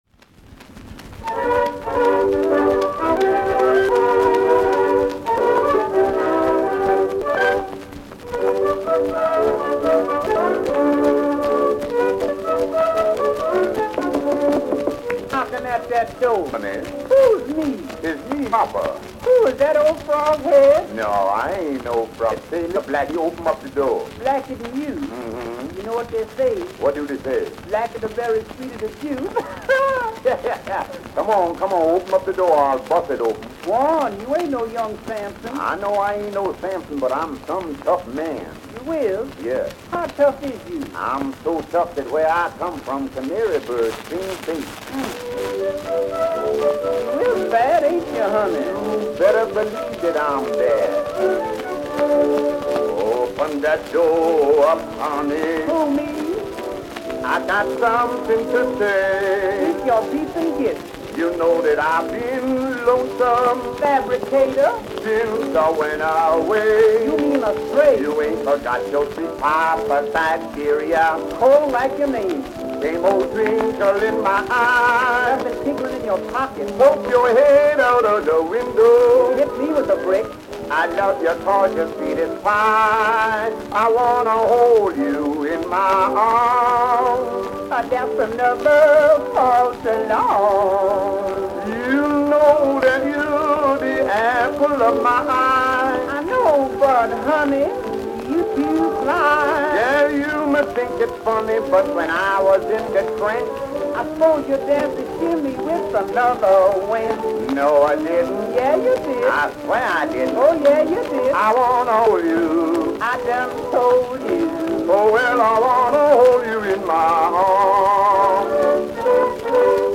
Popular music—1911-1920.